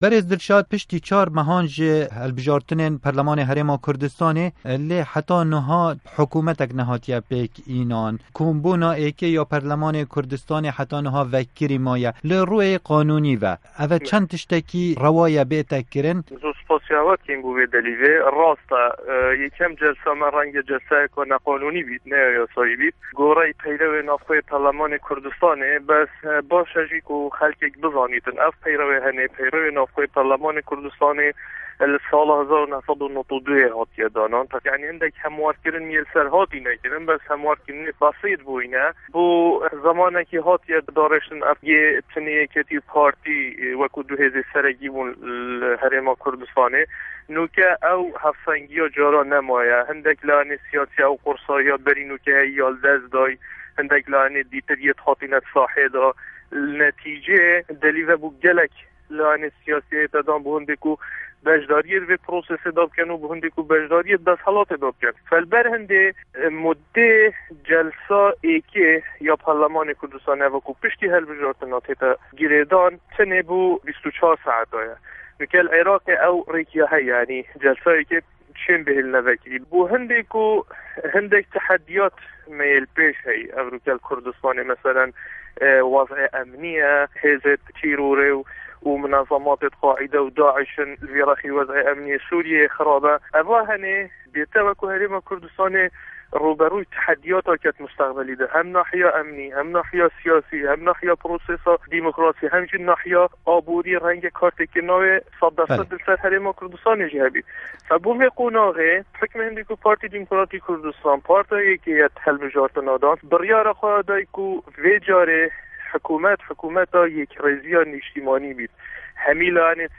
Di hevpeyvînekê de ligel Dengê Amerîka, endamê perlamana Herêma Kurdistanê Dilşad Şaban Xefar dibêje, rêkeftina stratejîk li navbara PDK û YNK li bîst salên borî bandoreke baş li ser rewşa herêma Kurdistanê